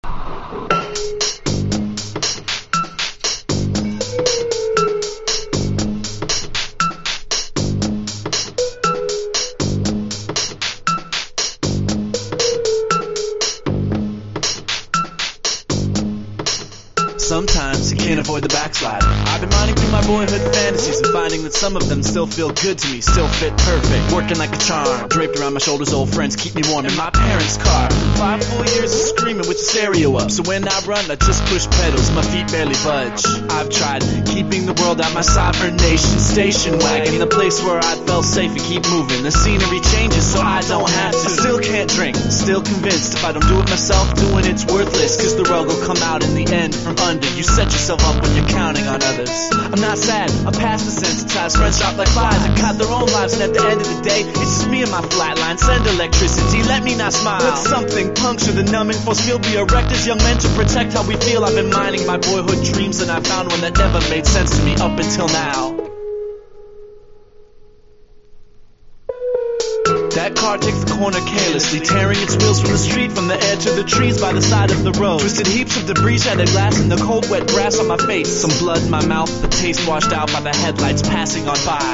UNDERGROUND HIPHOP# BREAK BEATS / BIG BEAT
ELECTRONICA / CHILOUT